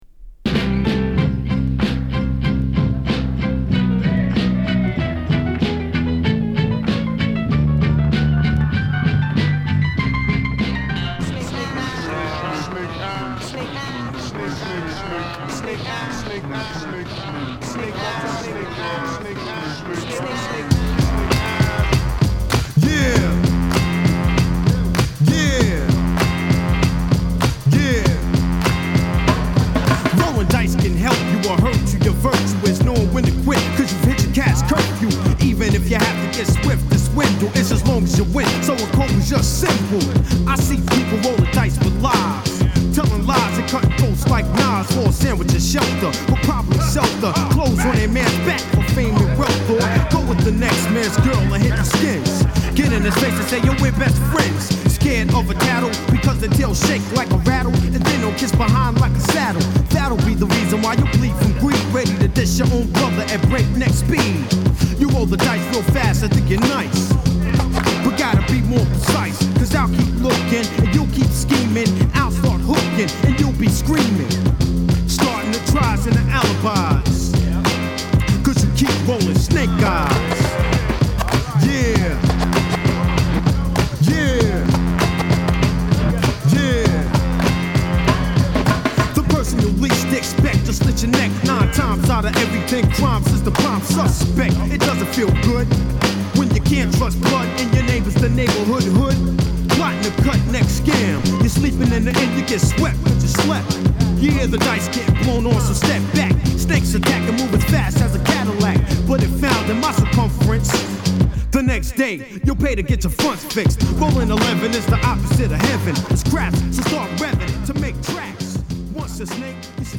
2LPで音圧もナイスです！
数回プチノイズ入ります。